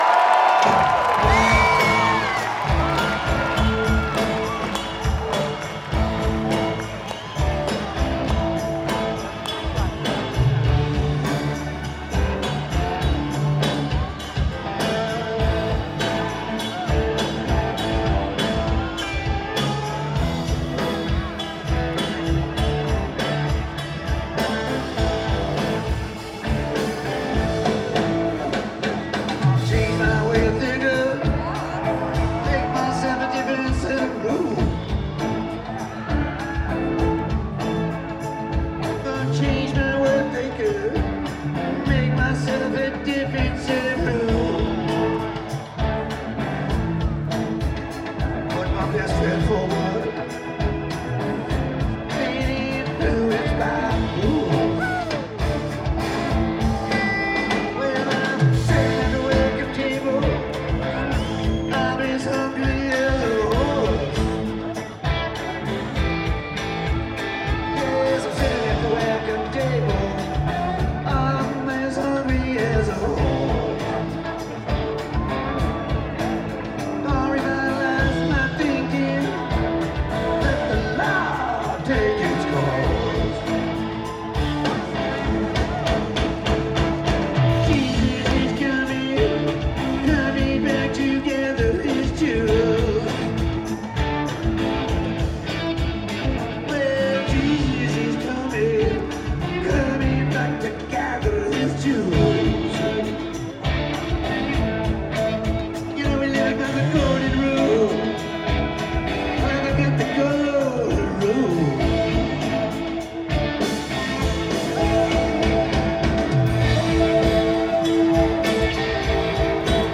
Greek Theatre - Berkeley, CA